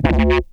Rave Bass 2.wav